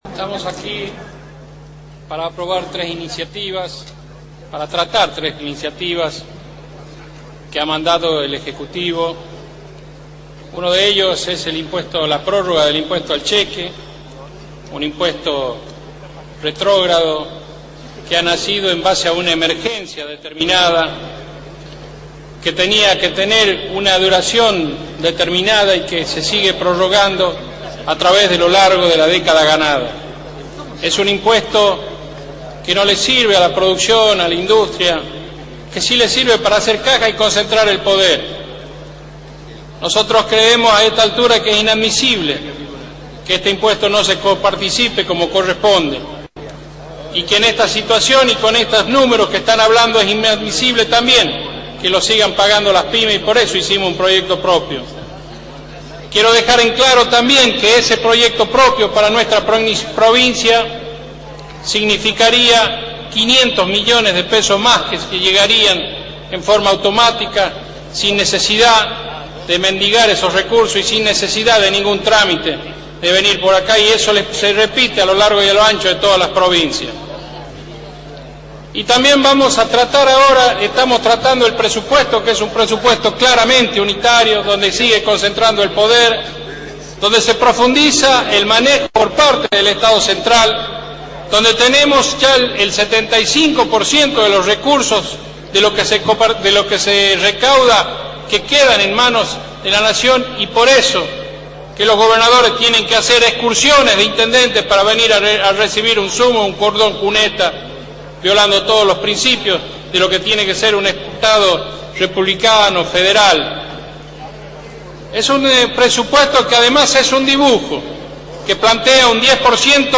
El legislador opositor hizo uso de la palabra en la sesión donde se debatía el presupuesto